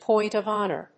póint of hónor